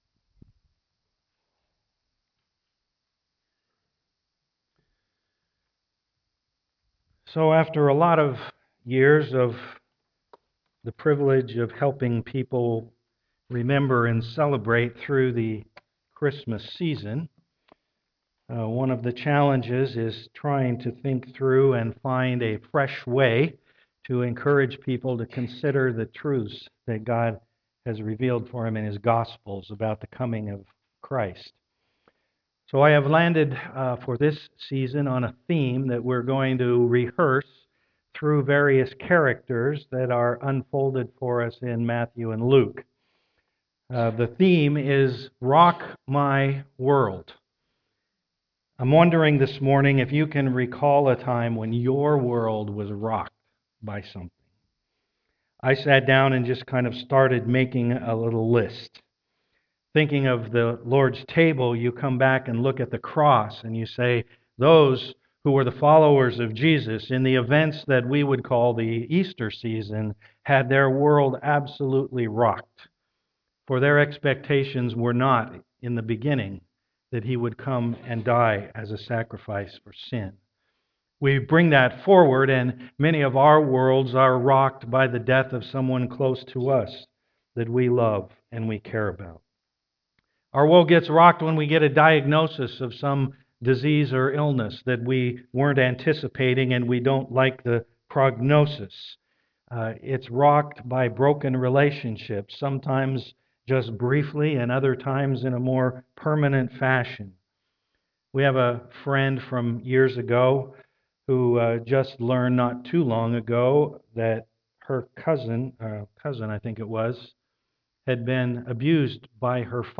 Luke 1:5-25 Service Type: am worship Discussion questions found on "bulletin" link below.